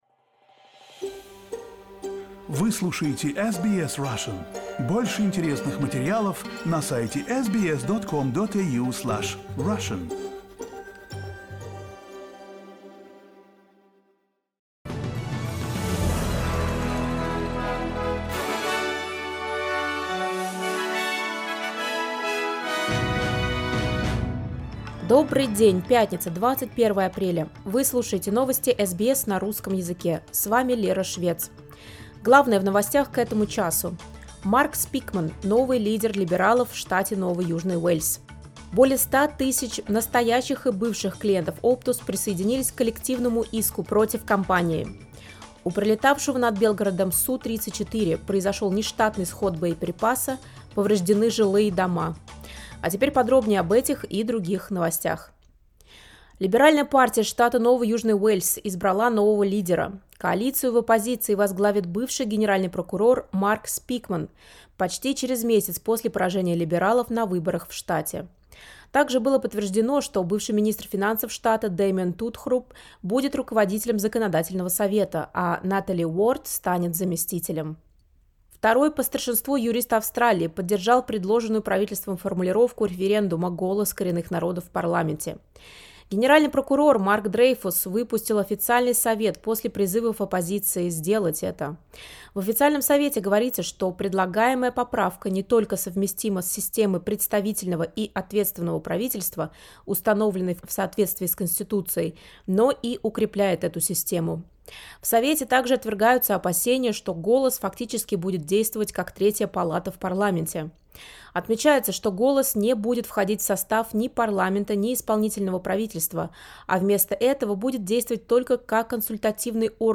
SBS news in Russian — 21.04.2023
Listen to the latest news headlines in Australia from SBS Russian